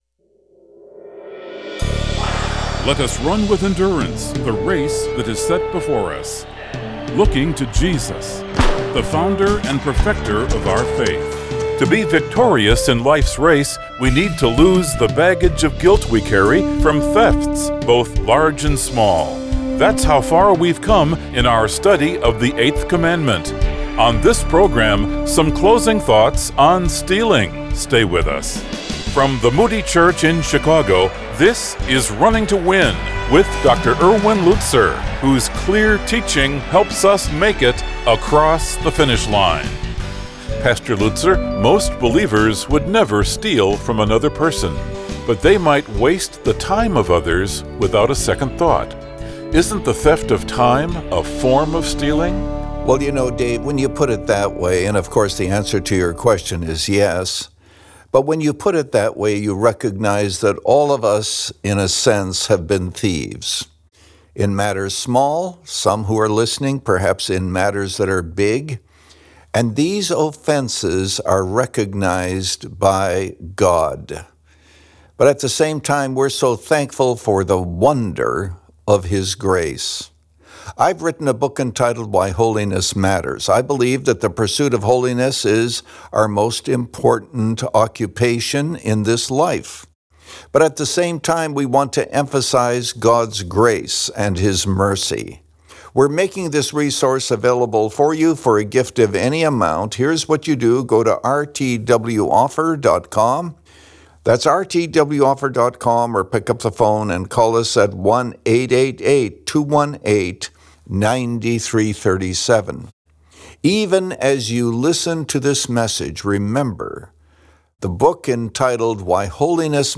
Playing By The Rules – Part 2 of 2 | Radio Programs | Running to Win - 25 Minutes | Moody Church Media